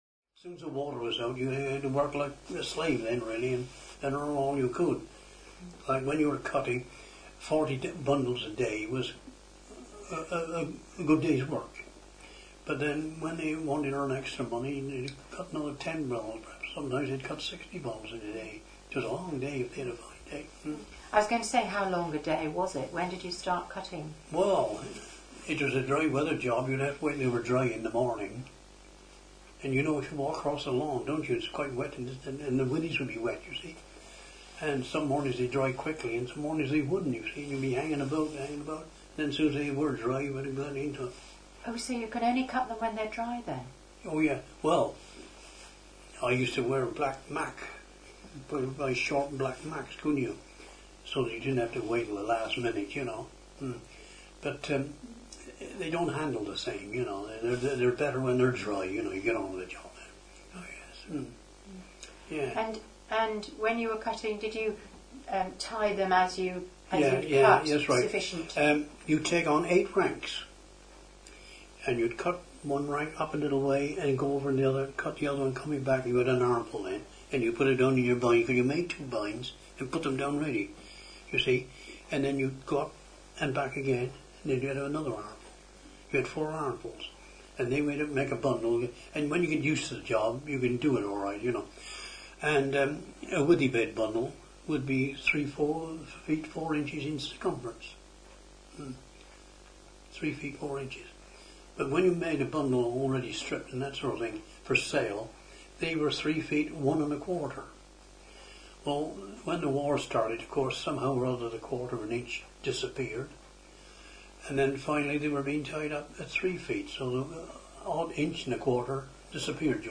For access to full interview please contact the Somerset Heritage Centre.